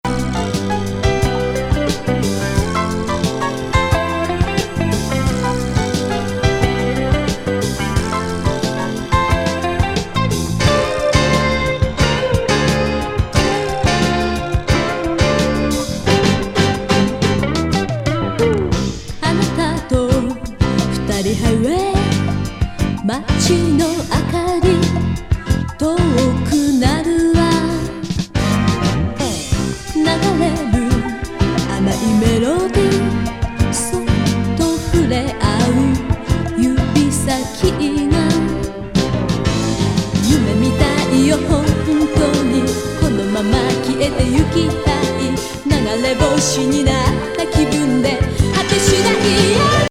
シンセ・ディスコ歌謡